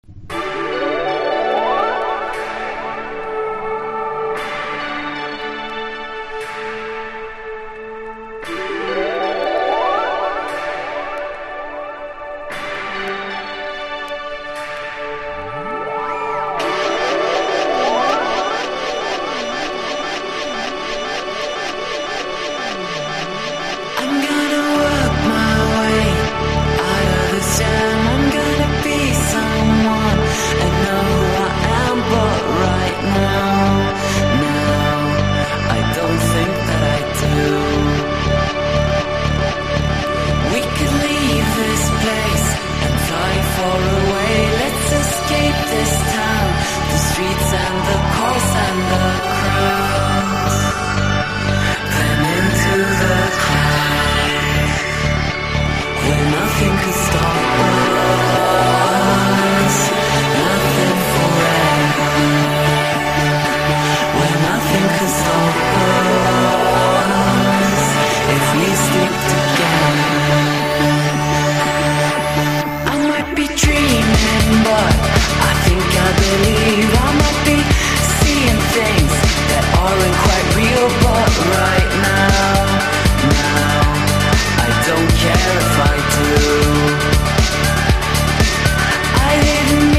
1. 00S ROCK >